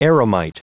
Prononciation du mot eremite en anglais (fichier audio)
Prononciation du mot : eremite